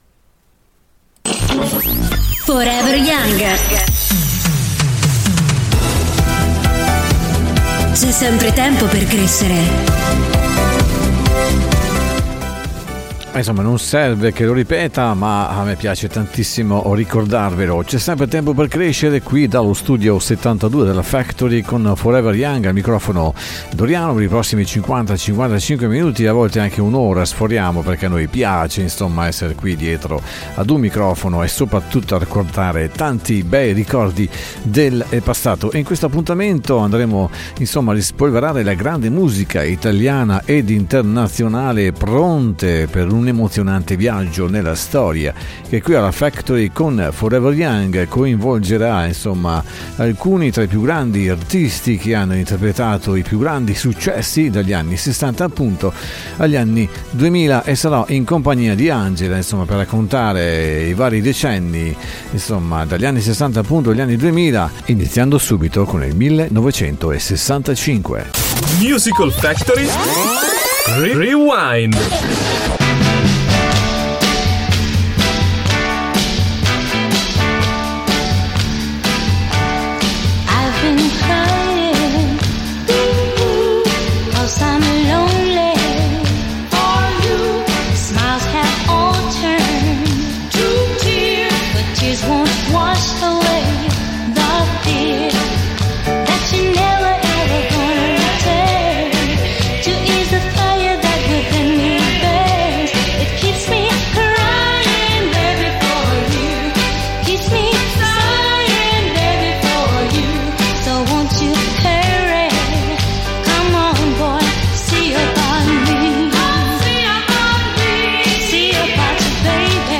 Dallo studio 72 di Forever Young raccontando la storia musicale appartenente ai cinque decenni ,dagli anni 60 agli anni 2000